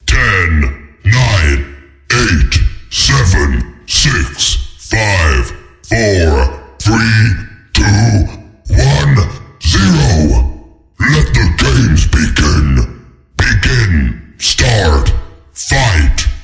Counting Numbers Soundpack » Counting Numbers Deep Epic Voice 012
描述：A deep voice is counting from zero to twelve.
标签： 110 voice eight nine 010 count two five cinematic 09 epic 2 3 eleven 1 three six 0 countdown counting 112 numbers zero four one deep number ten seven twelve
声道立体声